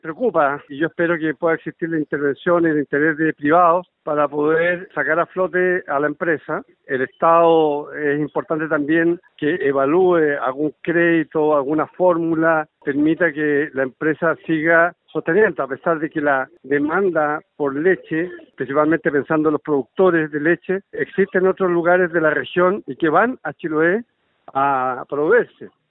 En tanto, el gobernador regional, Alejandro Santana, manifestó su esperanza en que exista disposición privada y estatal para apoyar a la empresa.